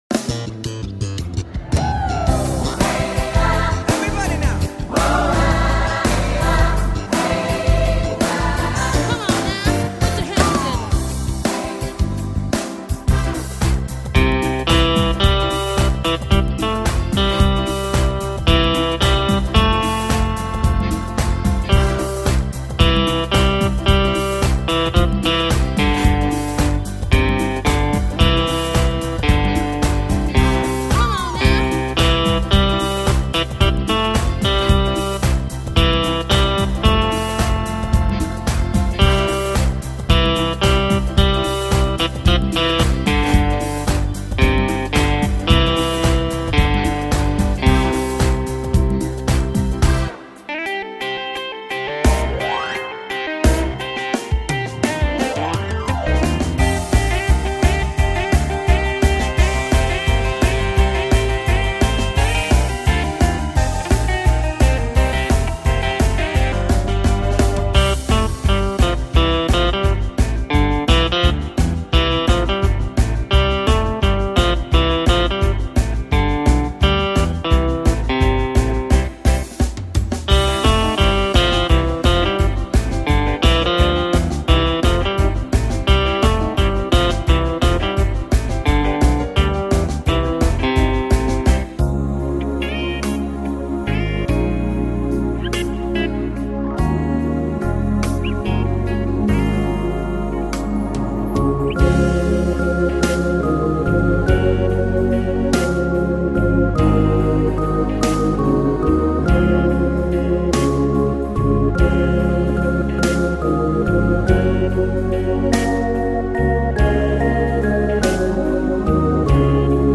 We have sung this songs in the school Tugbungan / Zamboanga and in Manila / Malabon LEARNING JOURNEY CHILD GROWTH CENTER